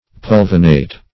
Search Result for " pulvinate" : The Collaborative International Dictionary of English v.0.48: Pulvinate \Pul"vi*nate\, Pulvinated \Pul"vi*na`ted\, a. [L. pulvinatus, fr. pulvinus a cushion, an elevation.] 1.
pulvinate.mp3